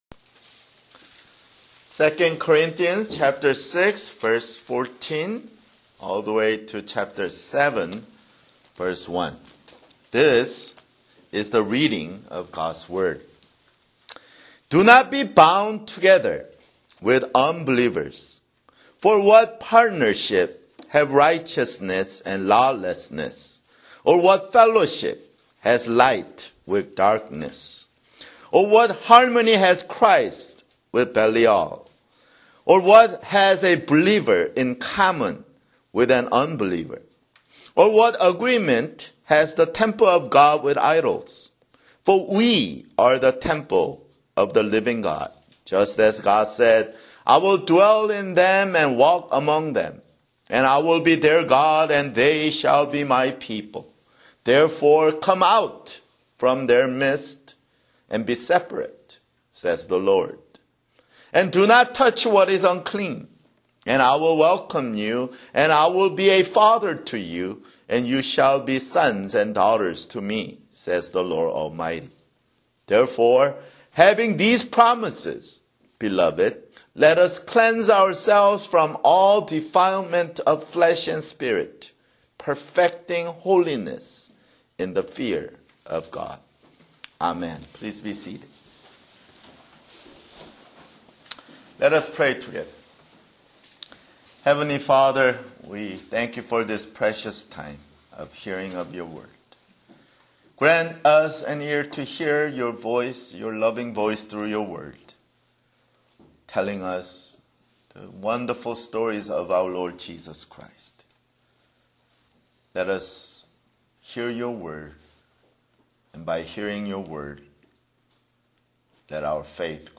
[Sermon] 2 Corinthians (35)